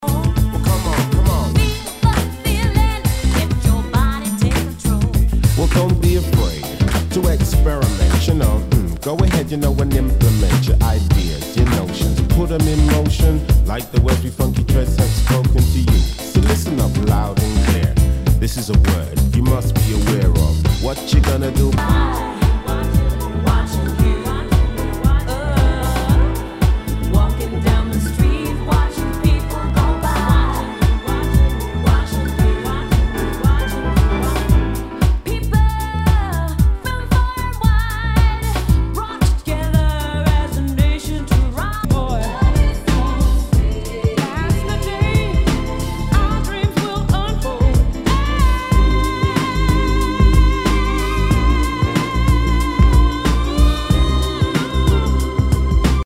Nu- Jazz/BREAK BEATS
アシッド・ジャズ / ダウンテンポ・クラシック！
全体にチリノイズが入ります